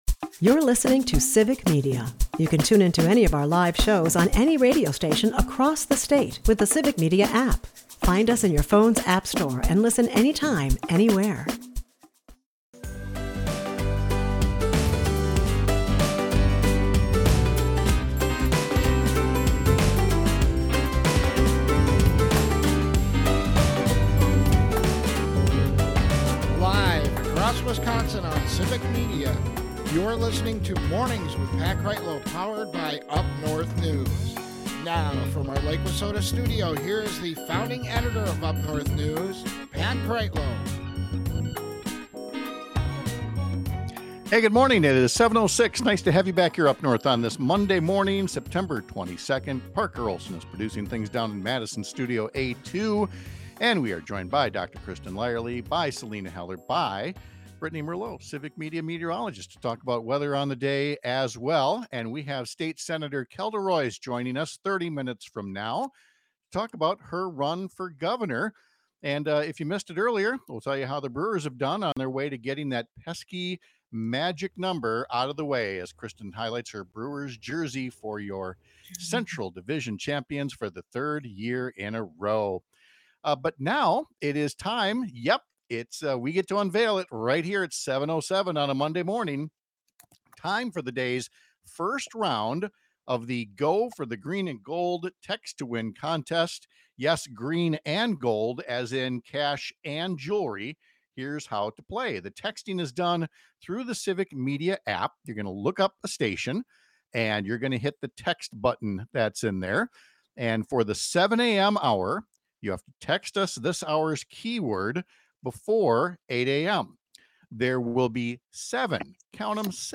(Hour 2) Guests: Kelda Roys Mon Sep 22, 2025 44:12 Listen Share State Sen. Kelda Roys joins us live to discuss the first week of her run for governor — the issues that propelled her to join the race and why she thinks she’ll emerge from a growing field of Democratic candidates seeking the nomination next year.